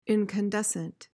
PRONUNCIATION:
(in-kuhn-DES-uhnt)